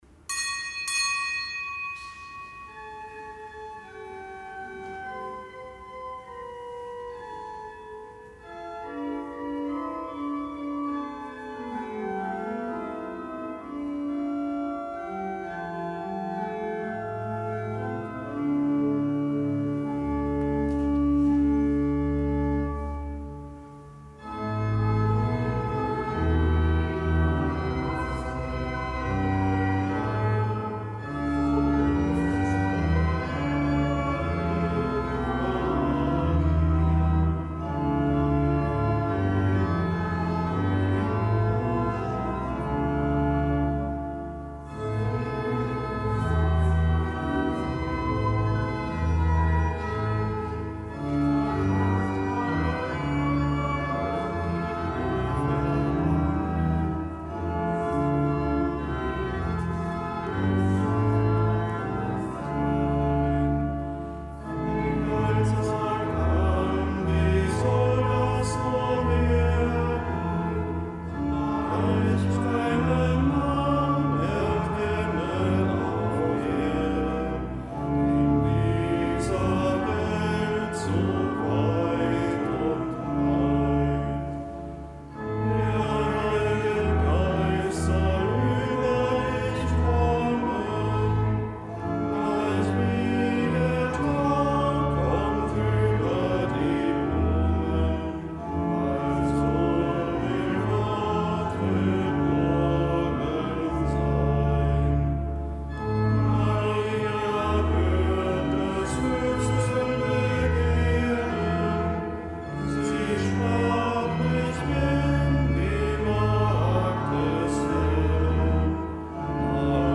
Kapitelsmesse aus dem Kölner Dom am Freitag der dritten Adventswoche.